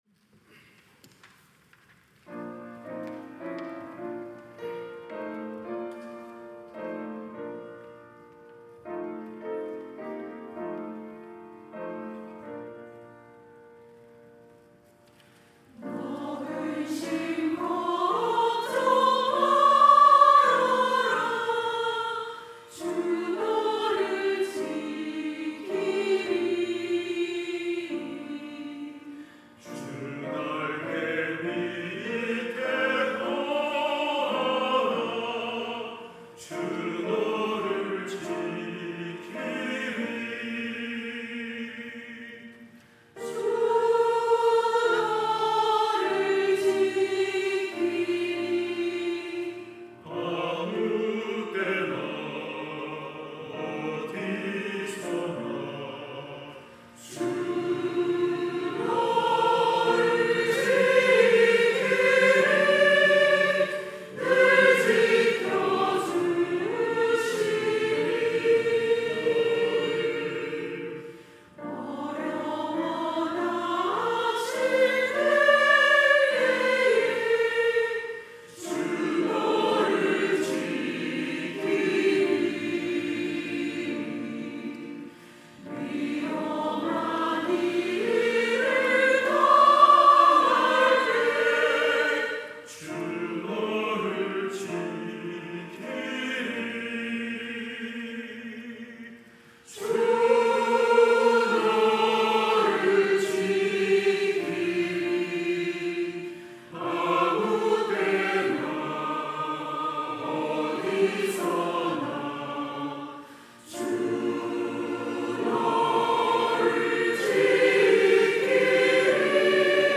찬양대 가브리엘